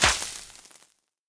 projectile-acid-burn-1.ogg